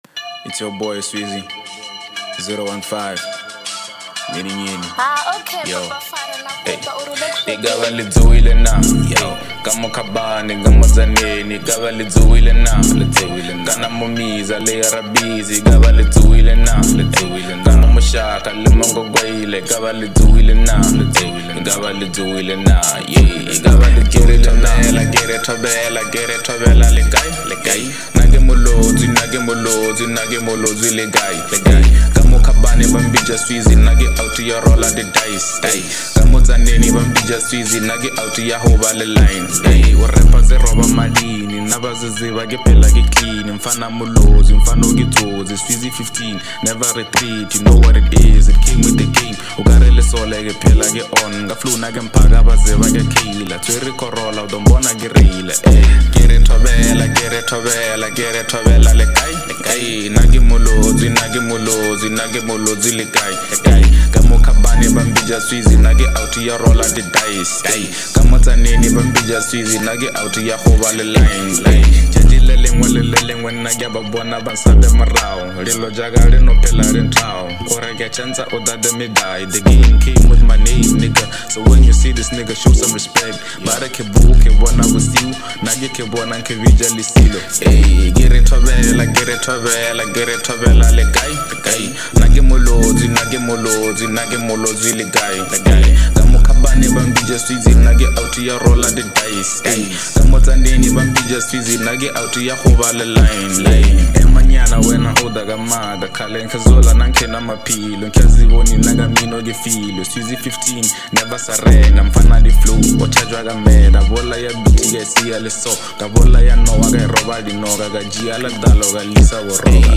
hipop
This song is Khelobedu Rap Music, called KheloRap.